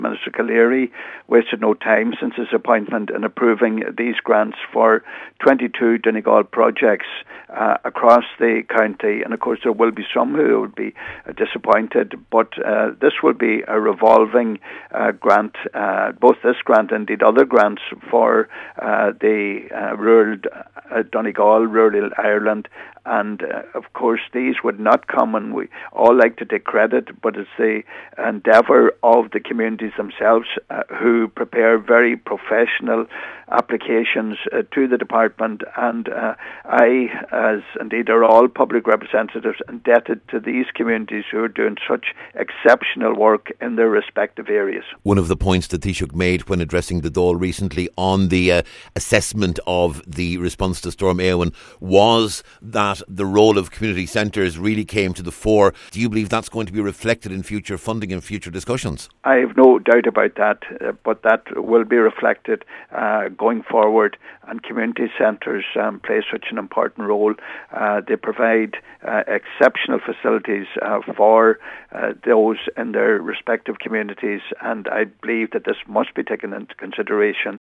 Welcoming the announcement, Deputy Pat The Cope Gallagher says the key role of community centres came to the fore this year during Storm Eowyn, and he expects that to be reflected in future funding announcements……..